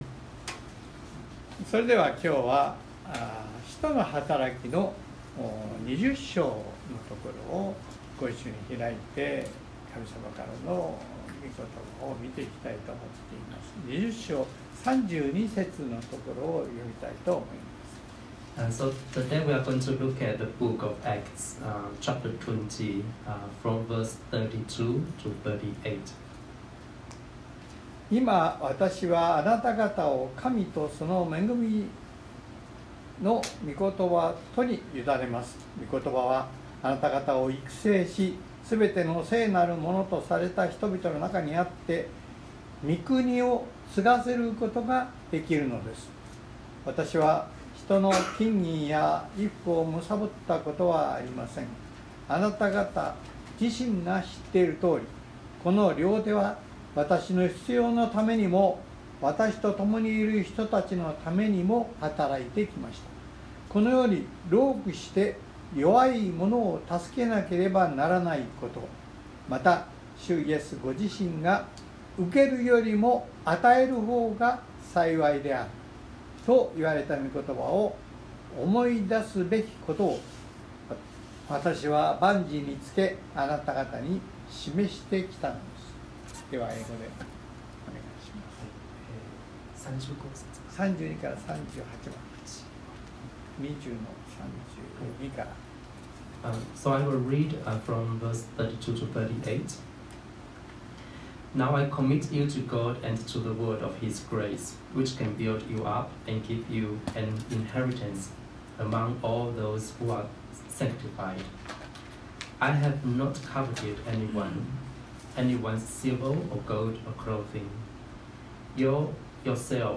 ↓Audio link to the sermon:(Sunday worship recording) (If you can’t listen on your iPhone, please update your iOS) Sorry, this post is no translate, only available in Japanese.